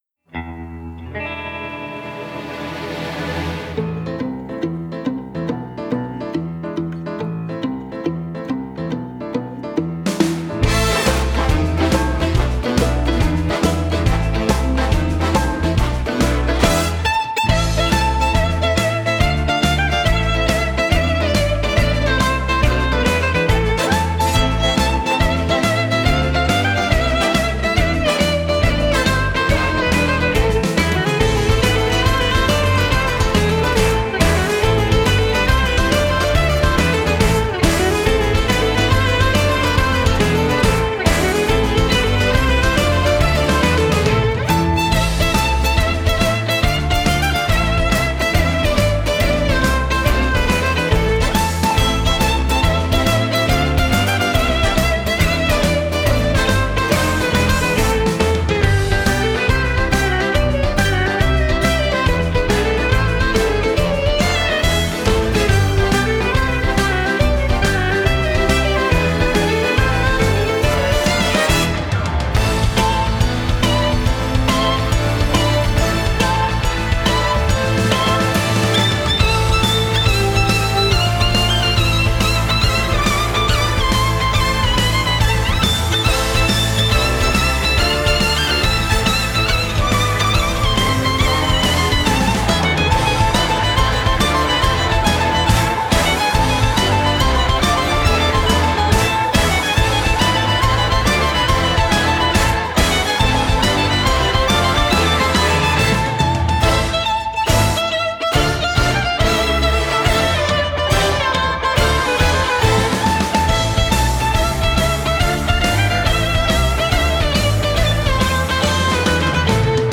Genre: Score
virtuoses Spiel mit modernen Klängen